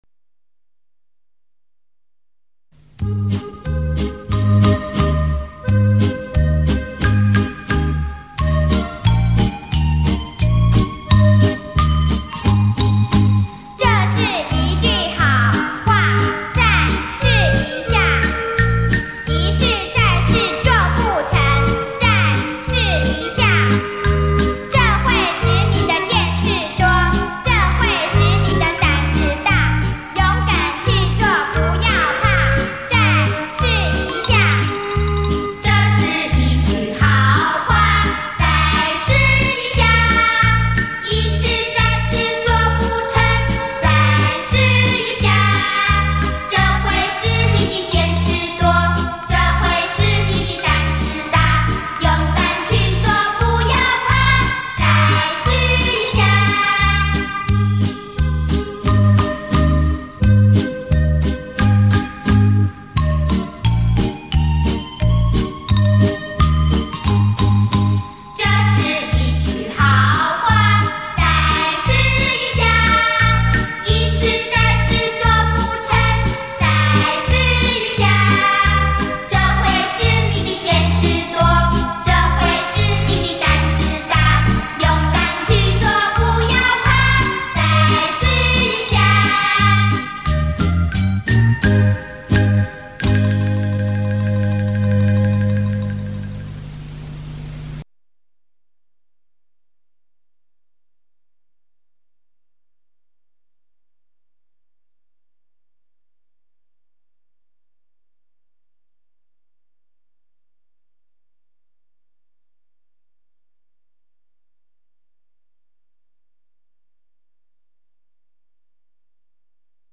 回复：[1/21/2008]再试一下（童谣）
变成双声道响